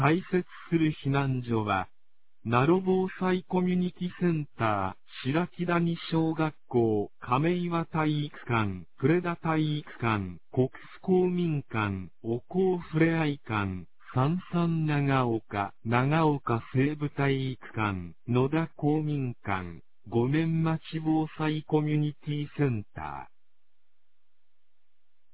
放送音声
2024年08月08日 21時34分に、南国市より放送がありました。